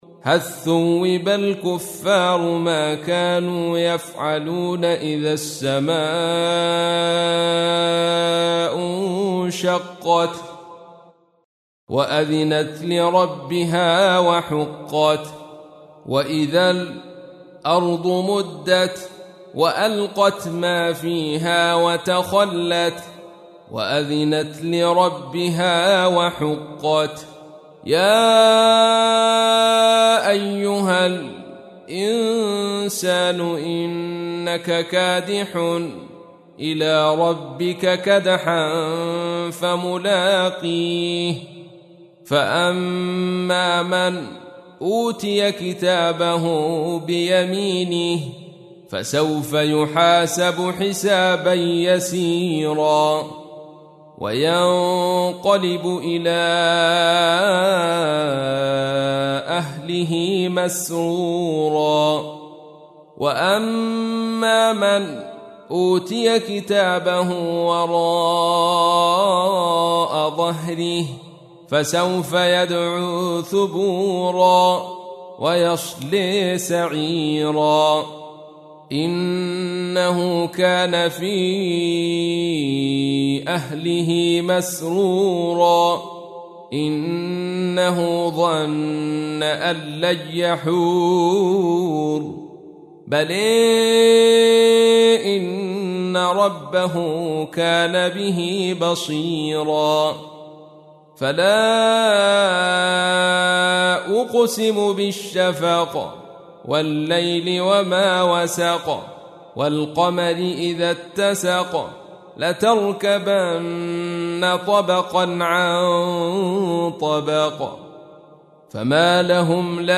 تحميل : 84. سورة الانشقاق / القارئ عبد الرشيد صوفي / القرآن الكريم / موقع يا حسين